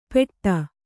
♪ peṭṭa